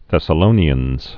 (thĕsə-lōnē-ənz)